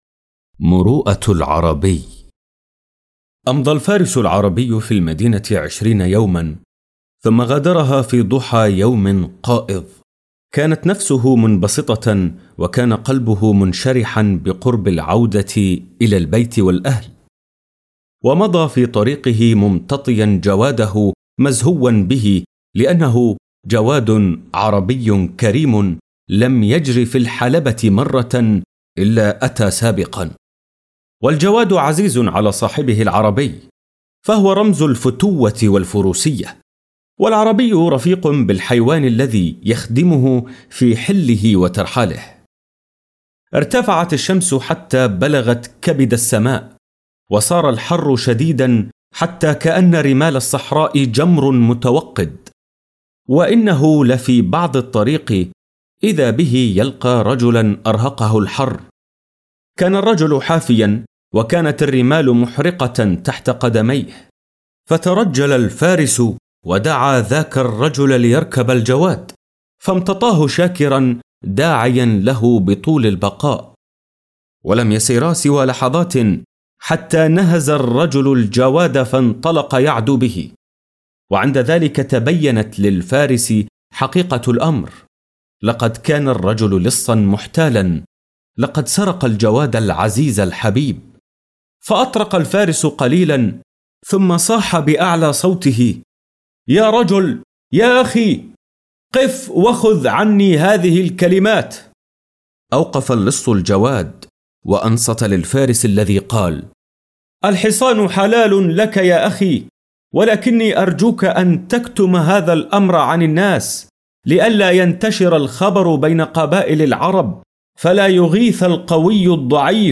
درس الاستماع مروءة العربي صف سابع فصل ثاني منهاج اردني 2024/2025